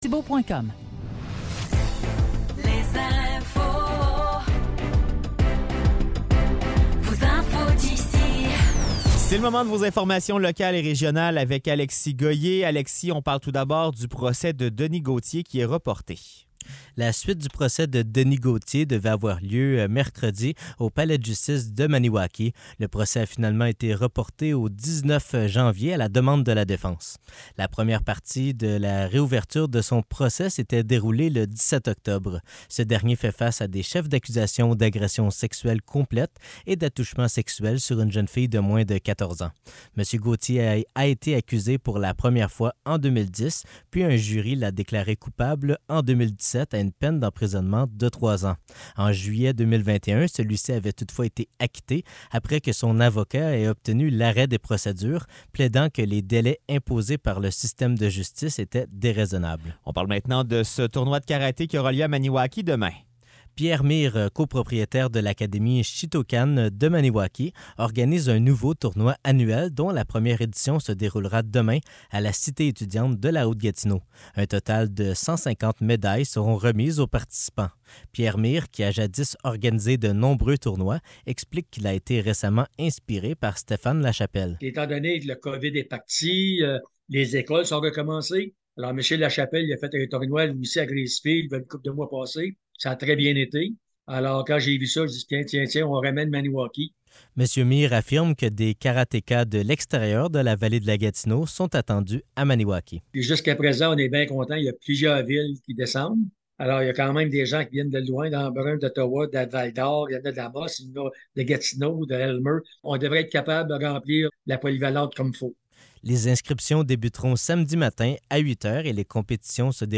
Nouvelles locales - 24 novembre 2023 - 16 h